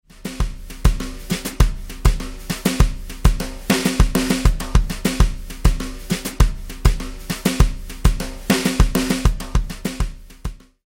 5段階のベロシティで鳴らしたサウンドサンプル(MP3, 128kbps)を試聴できます。